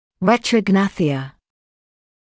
[ˌretrəu’gnæθɪə][ˌрэтроу’гнэсиэ]ретрогнатия